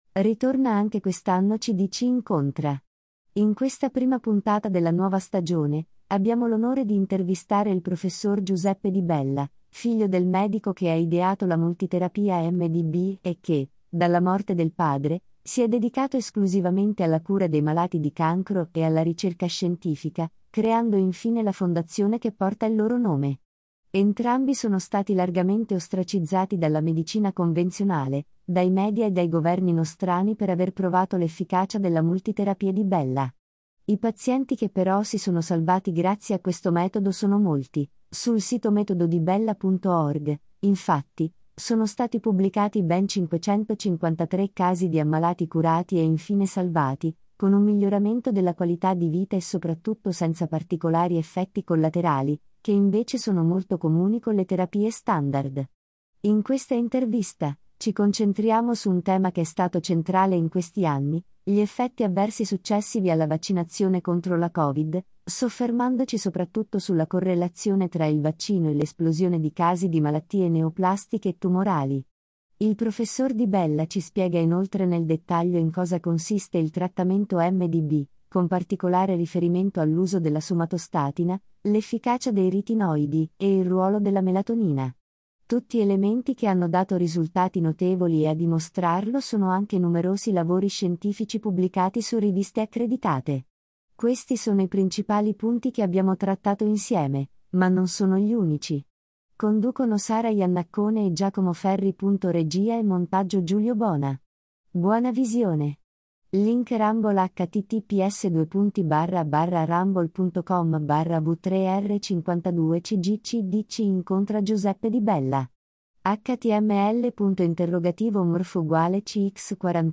In questa intervista, ci concentriamo su un tema che è stato centrale in questi anni: glisuccessivi alla vaccinazione contro la Covid, soffermandoci soprattutto sulla correlazione tra ile l’esplosione di casi di malattie neoplastiche e tumorali.